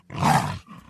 Звуки ягуара
Детеныш ягуара грозно рычит